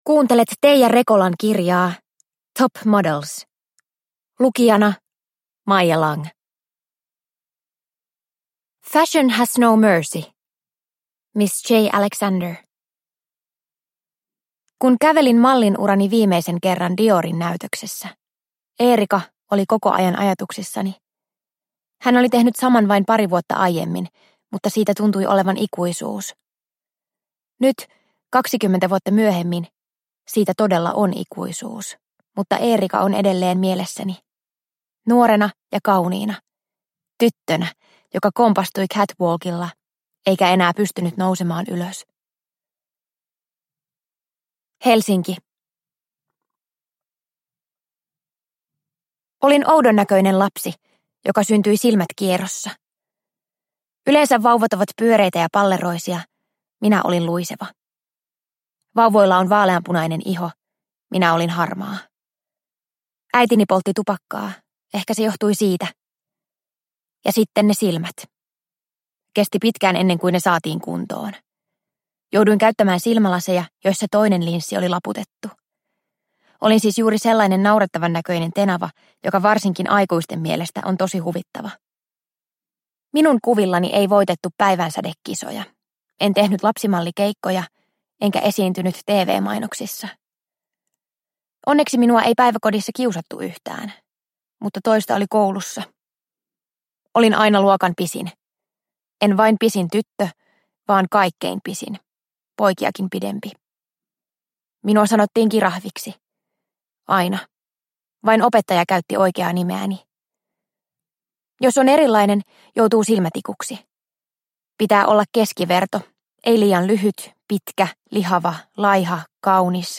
Top Models – Ljudbok – Laddas ner